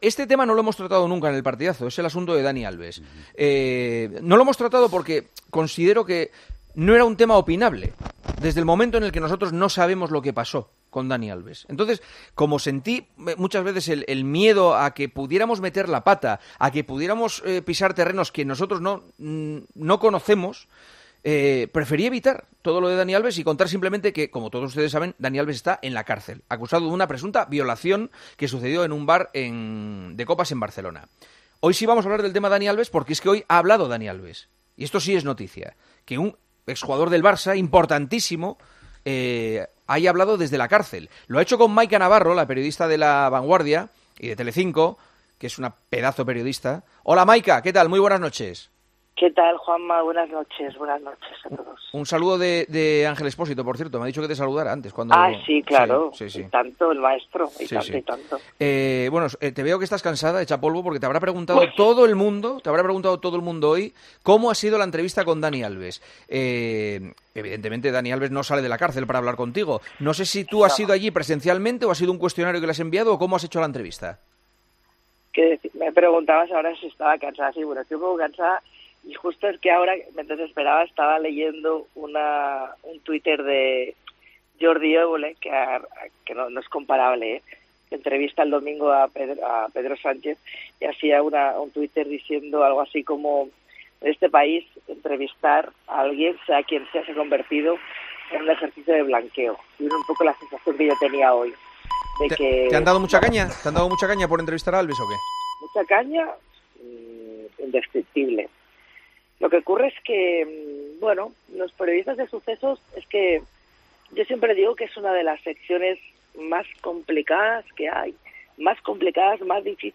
AUDIO: Juanma Castaño habla con la periodista de La Vanguardia, la primera en entrevistar al futbolista brasileño en la cárcel acusado de violación.